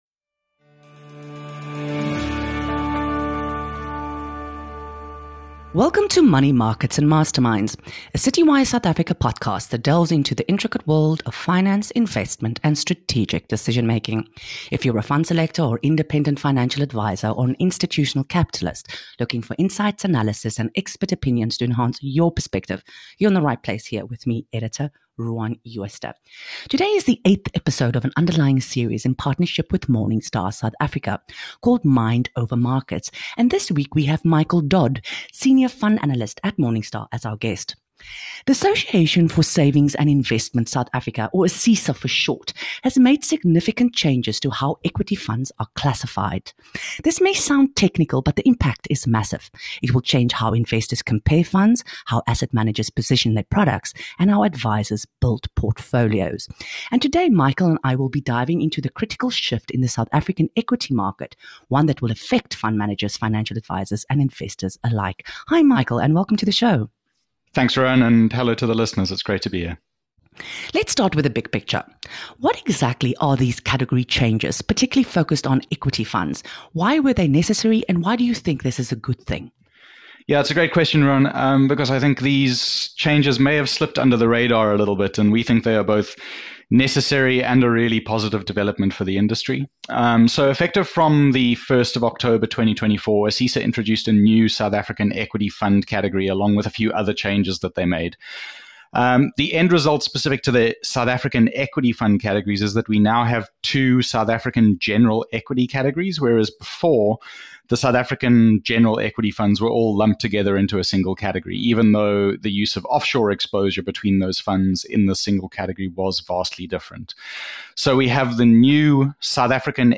Listen to our conversation on how fund managers, DFMs, and retail investors should navigate these changes and what trends will shape SA equity funds going forward.